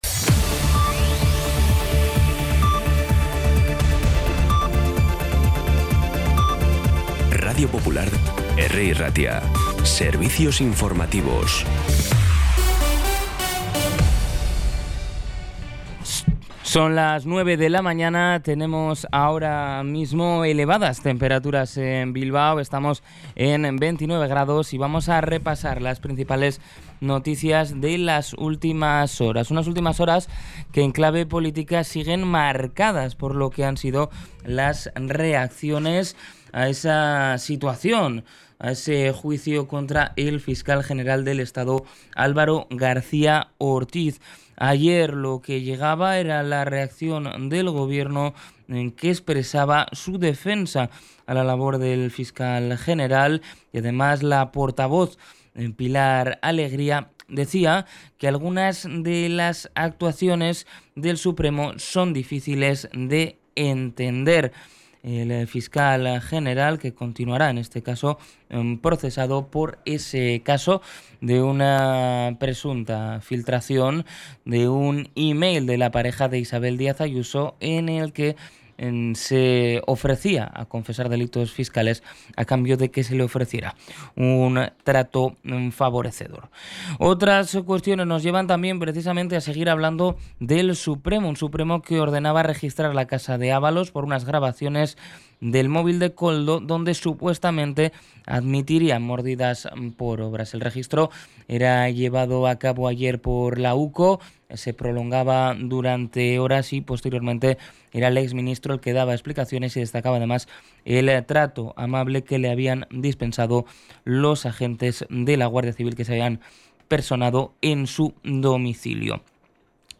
Las noticias de Bilbao y Bizkaia del 11 de junio a las 9
Los titulares actualizados con las voces del día. Bilbao, Bizkaia, comarcas, política, sociedad, cultura, sucesos, información de servicio público.